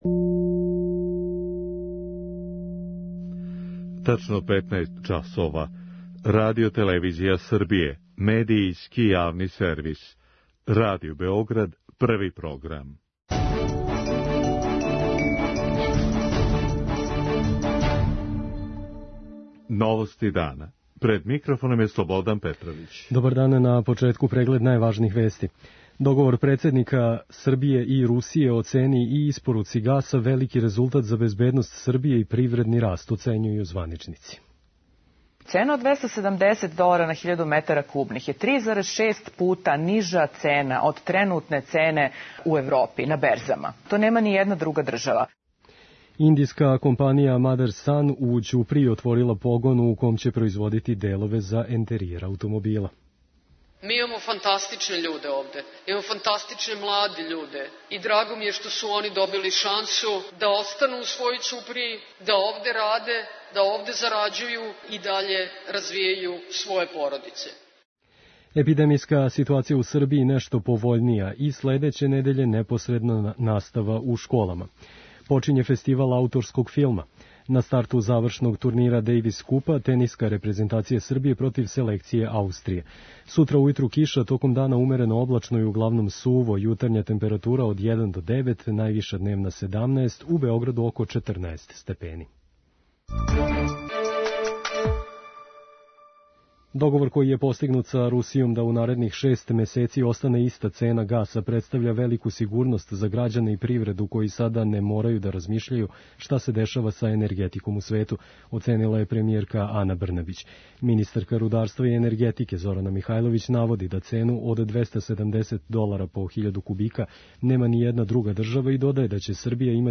централна информативна емисија